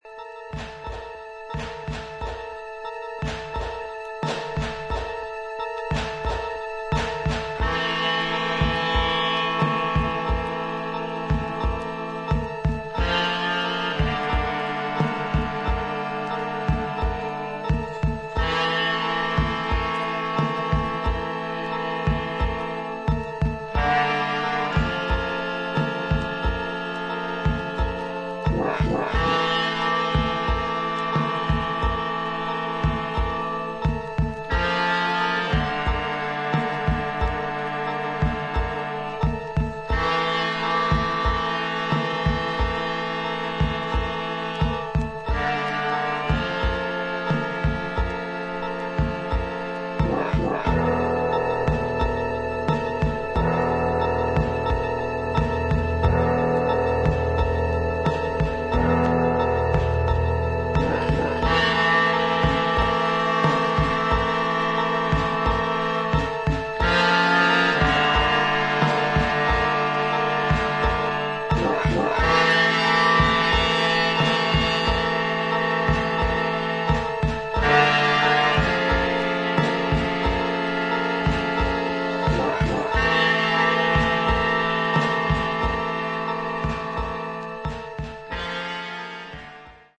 80'sエレクトロニックな感覚とシネマティックな雰囲気が随所に感じられるコンセプチュアルなコンピレーション作品